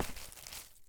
Divergent / mods / Footsies / gamedata / sounds / material / human / step / earth2.ogg